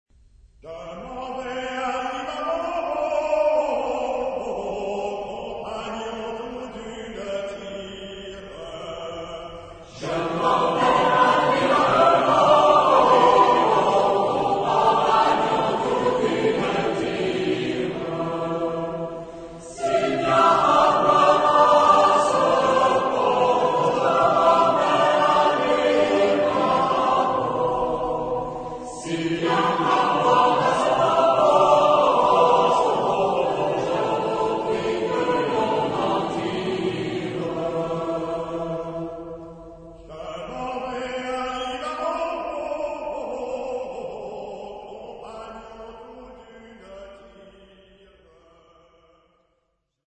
Genre-Style-Form: Popular ; Secular
Mood of the piece: lively
Type of Choir: TBarB  (3 men voices )
Tonality: E minor